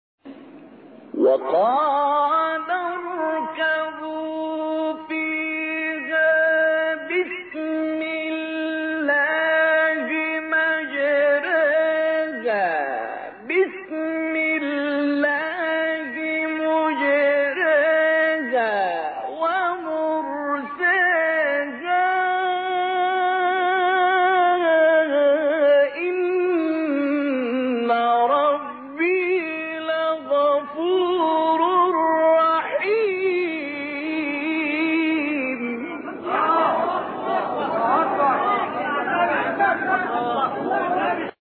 گروه شبکه اجتماعی: مقاطعی صوتی از تلاوت قاریان برجسته مصری ارائه می‌شود.
مقطعی از عبدالمنعم طوخی در مقام بیات